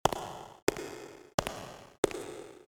Footsteps Cave Shoes Walk